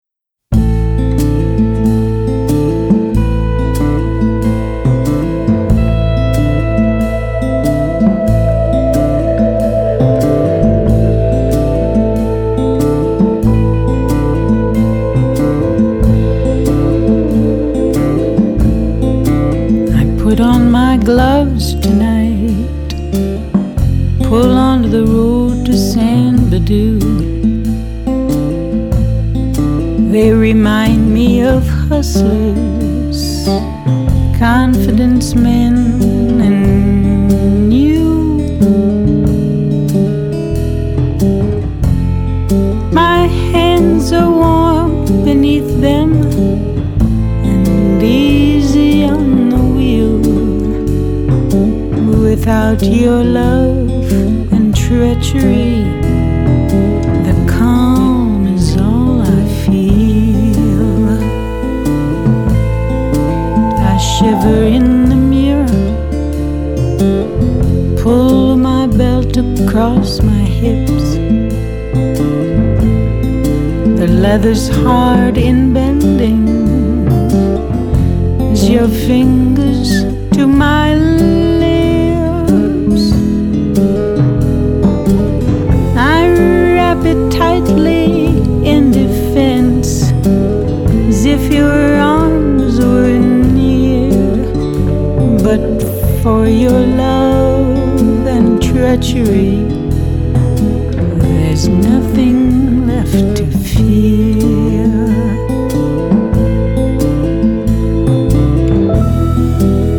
爵士及藍調 (573)
採用現場收音方式錄製，完全不以混音處理
完美呈現醇美嗓音與絕妙爵士情境！